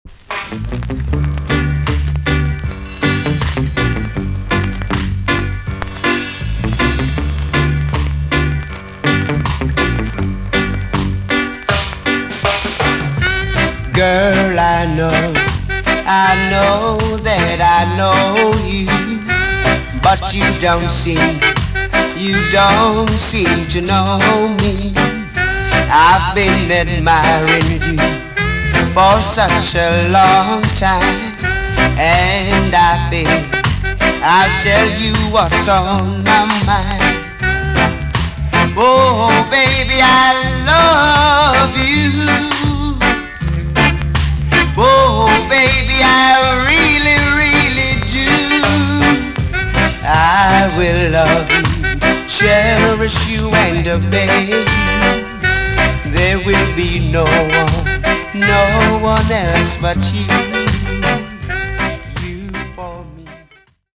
TOP >REGGAE & ROOTS
VG+ 少し軽いチリノイズがありますが良好です。
NICE VOCAL TUNE!!